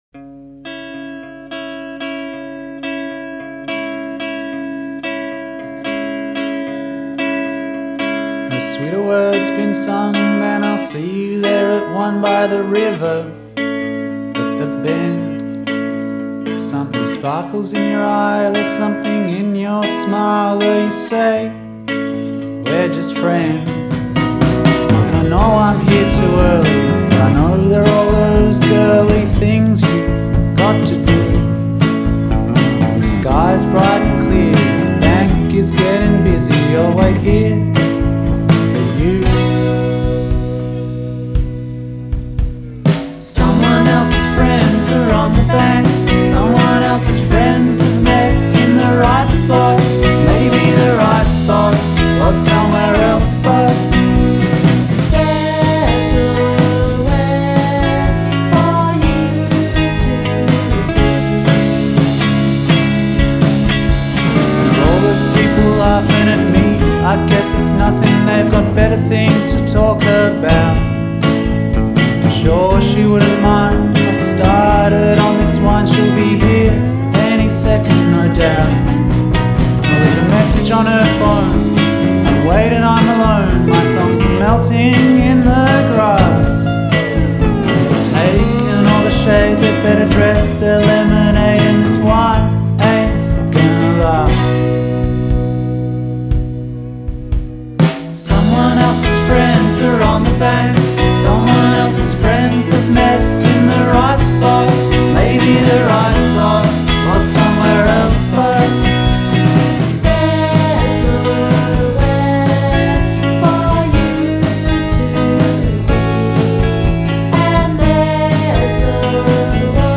Album Reviews, Guitar Rock, Indie Rock, Psych